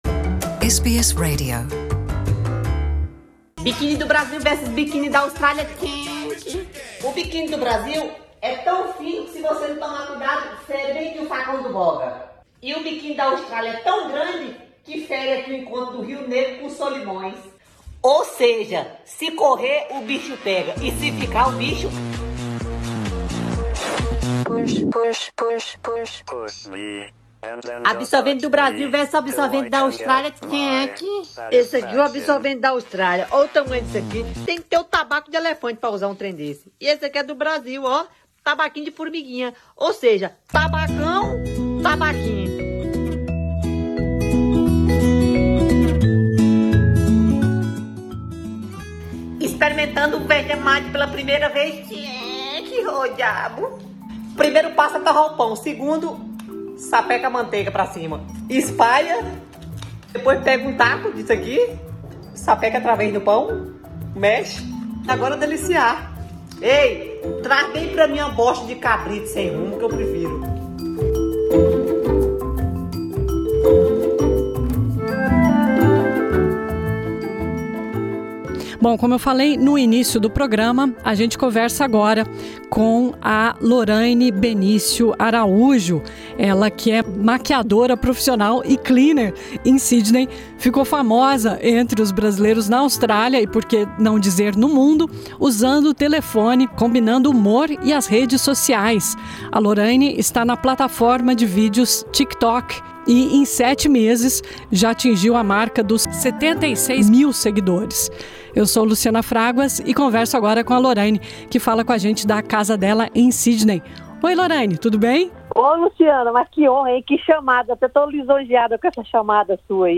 Entrevista foi originalmente ao ar em Dezembro de 2020 O aplicativo de streaming de vídeo TikTok se tornou um fenômeno entre os adolescentes, principalmente durante o período de lockdown.